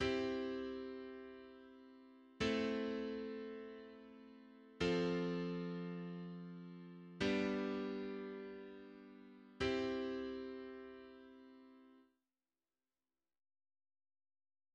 Последовательность 50-х годов в до мажоре